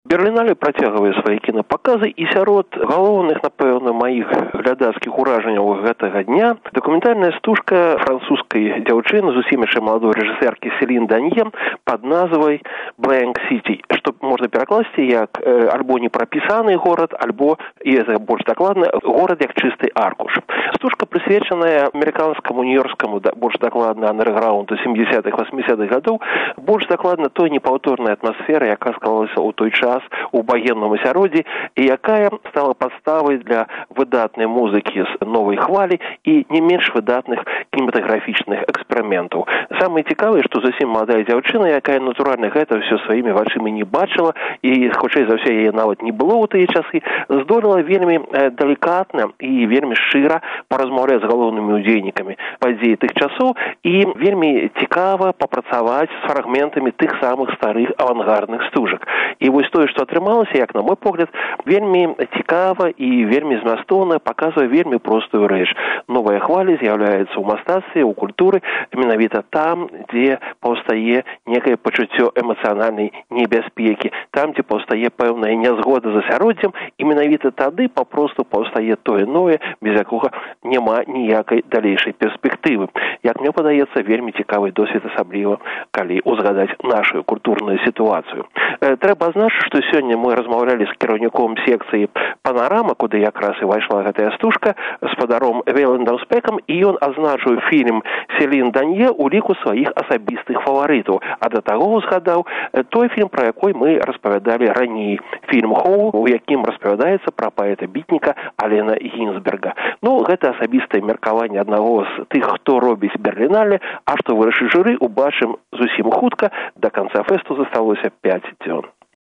“Бэрлінале”: дзень чацьверты, рэпартаж зь месца падзеяў